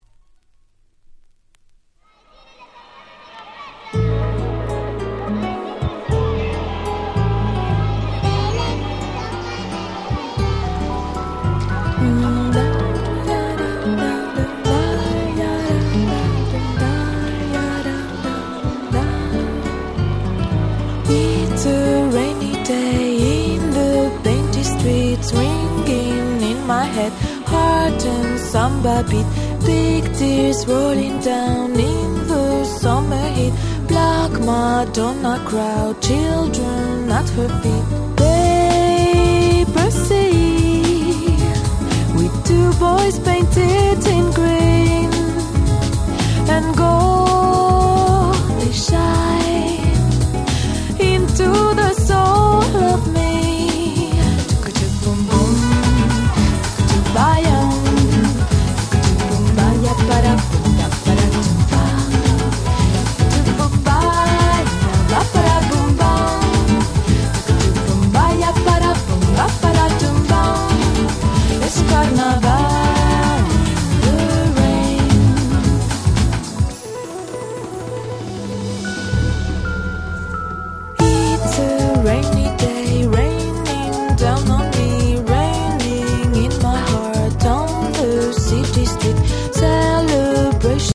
UK出身の美人女性シンガーでシングルは93年からリリースしておりました。
Crossoverな層に受けそうな非常にオシャレな楽曲ばかりです！
Bossa